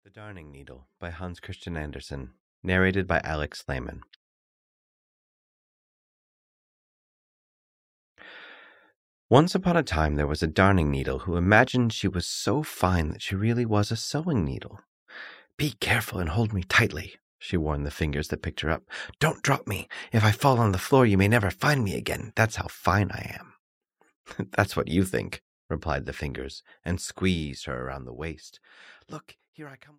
The Darning Needle (EN) audiokniha
Ukázka z knihy